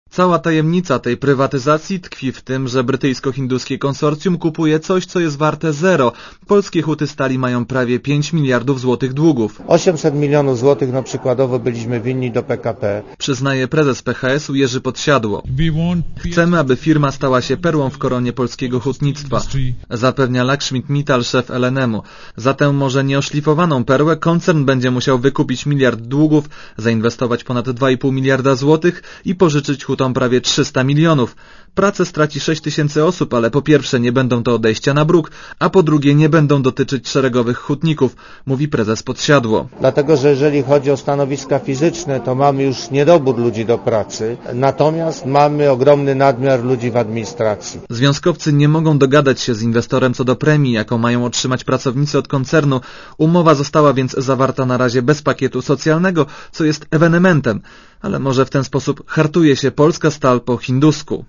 Komentarz audio (240Kb)